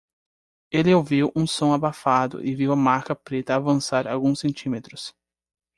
Pronounced as (IPA)
/a.vɐ̃ˈsa(ʁ)/